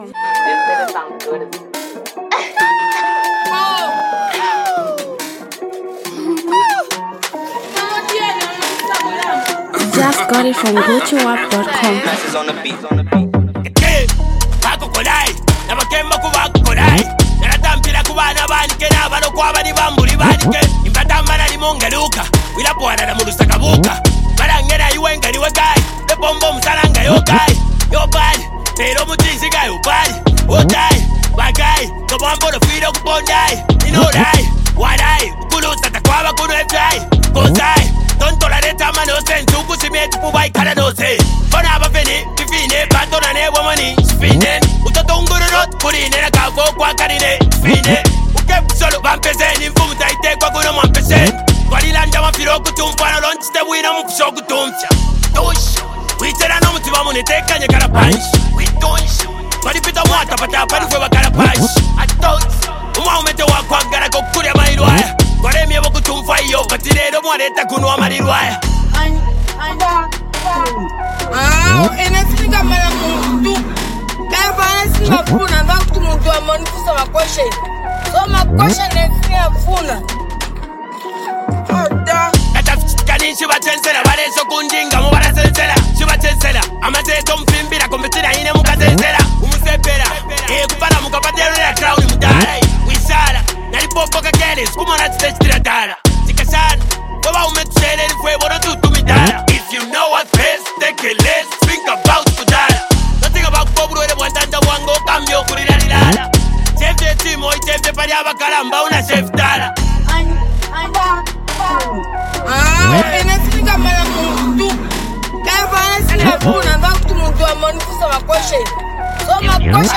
A raw freestyle